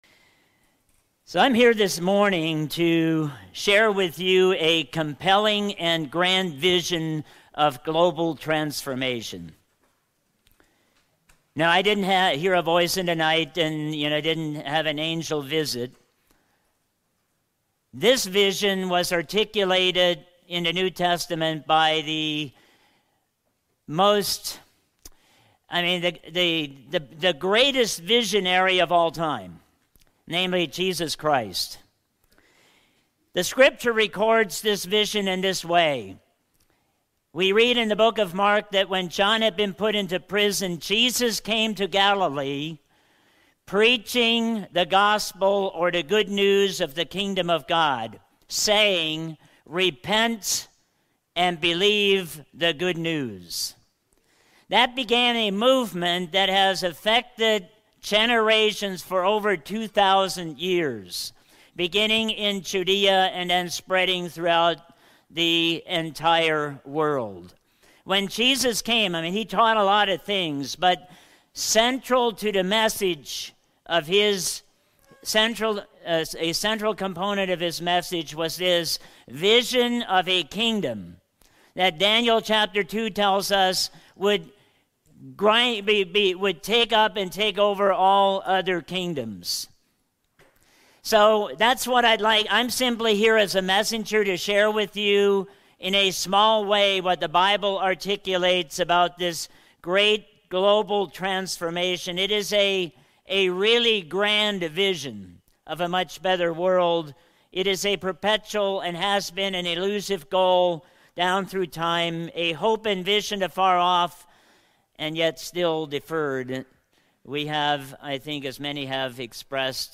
Sermons
Given in North Canton, OH Sugarcreek, OH